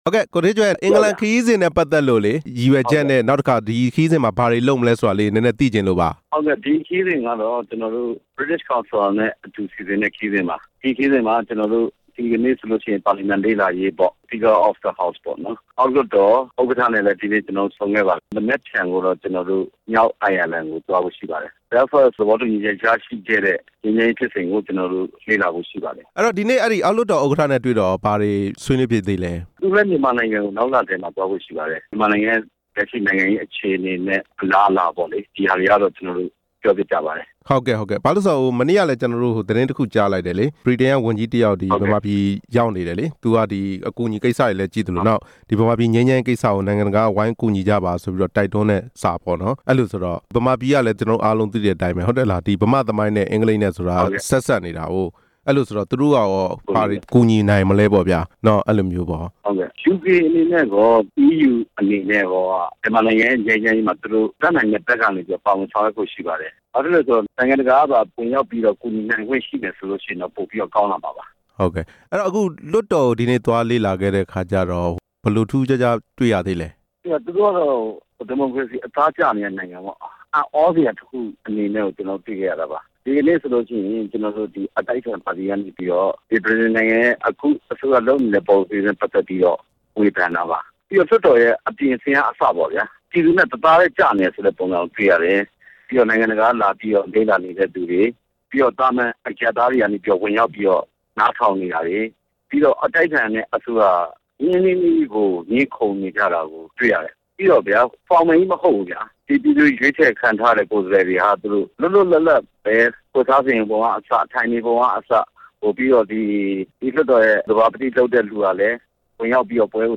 ကိုဌေးကြွယ်နဲ့ မေးမြန်းချက်